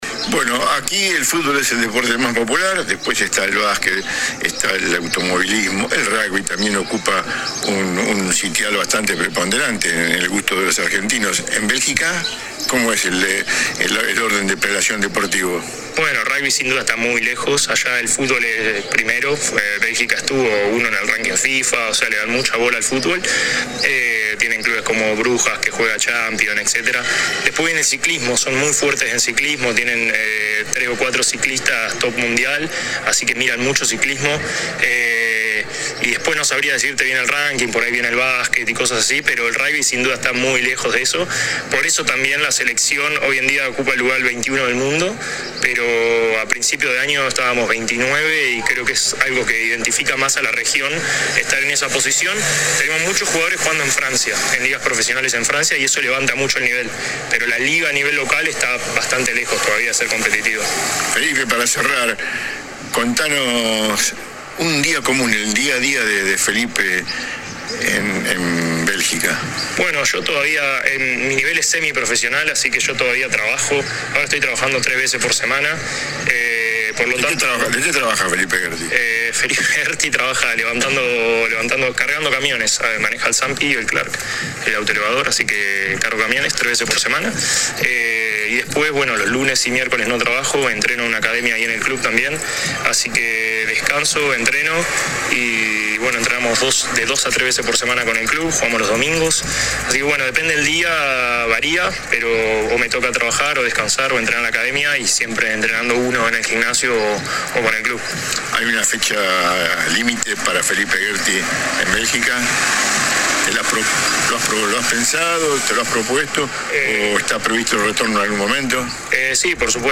AUDIOS DE LA ENTREVISTA